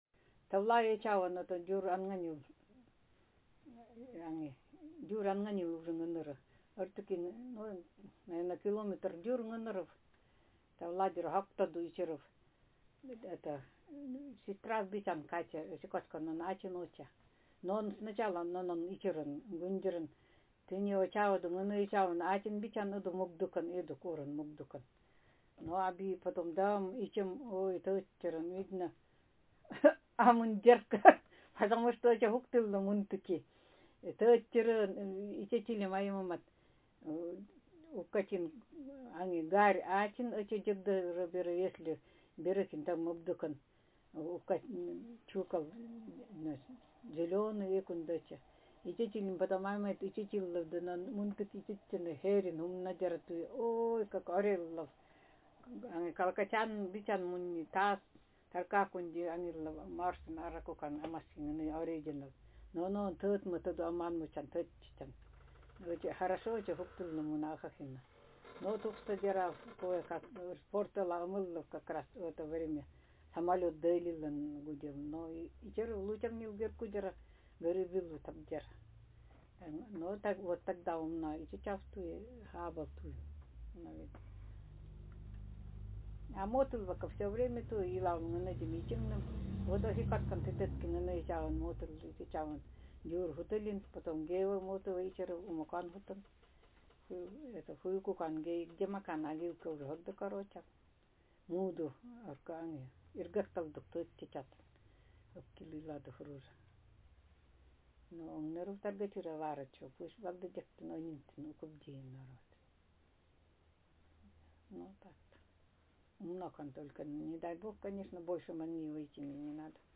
Speaker sexf
Text genrepersonal narrative